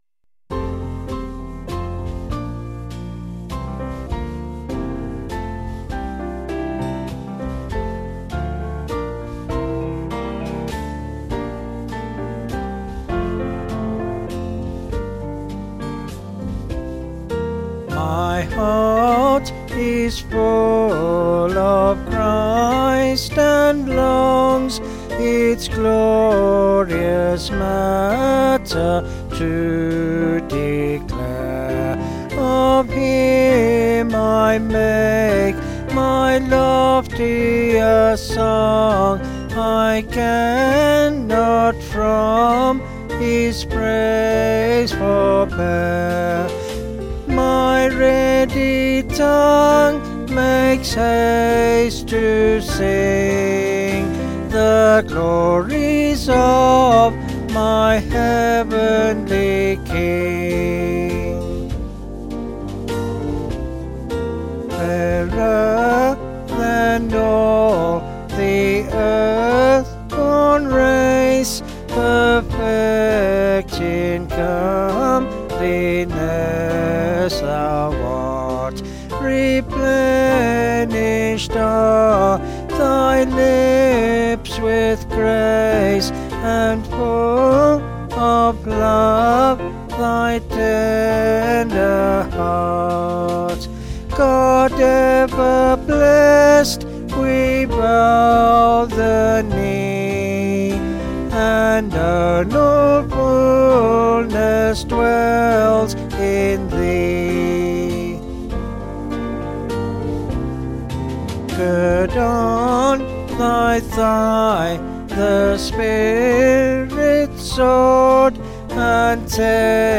Vocals and Band   261.6kb Sung Lyrics